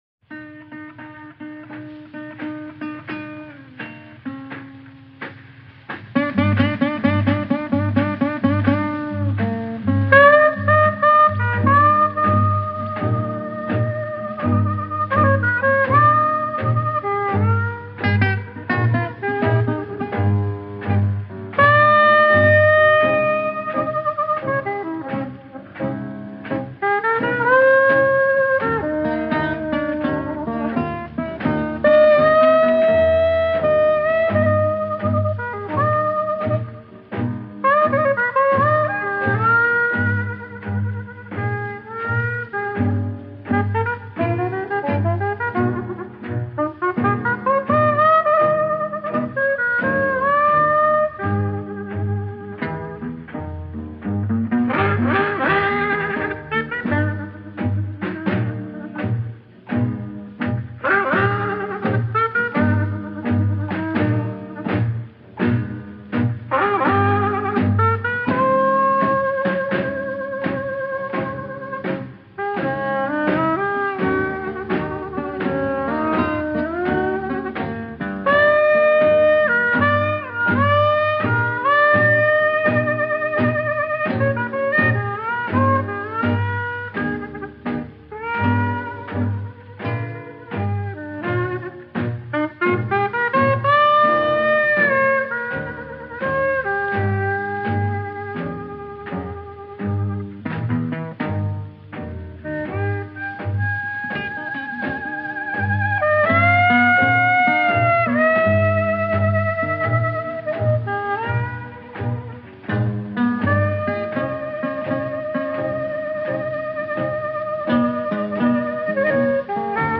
ineffably beautiful
guitar